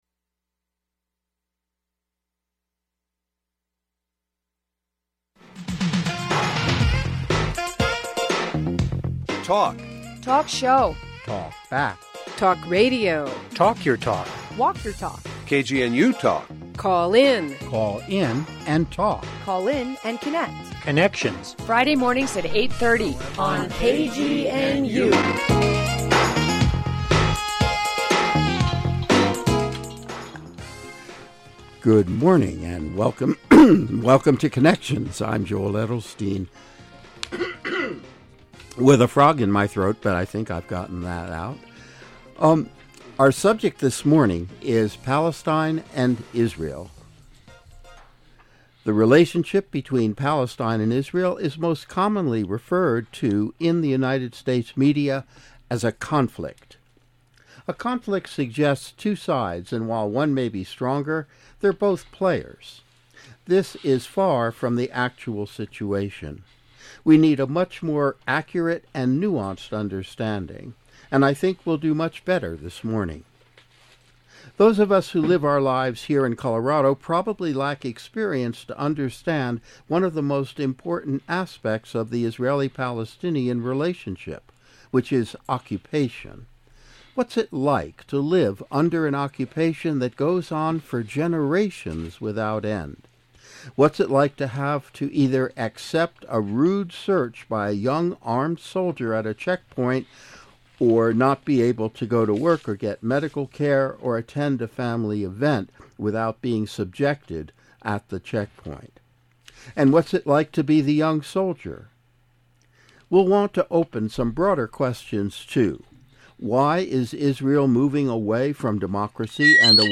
The interview centered on the state of relations between the Israeli government and the Palestinians living under Occupation.